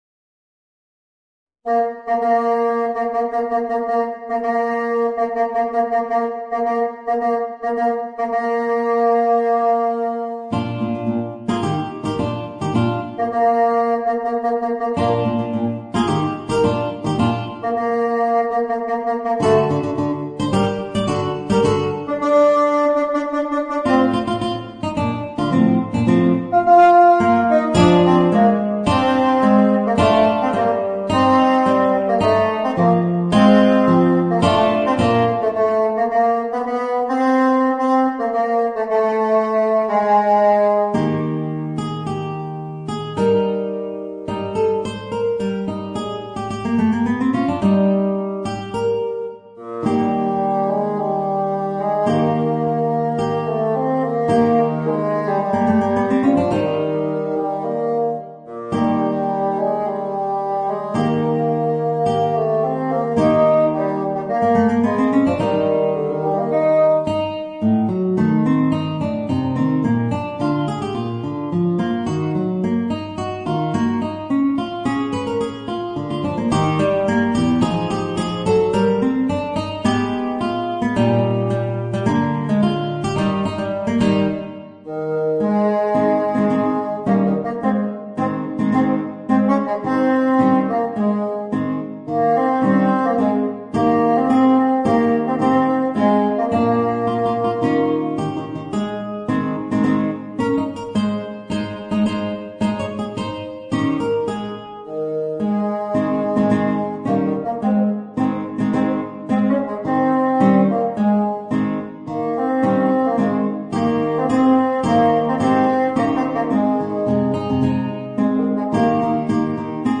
Voicing: Bassoon and Guitar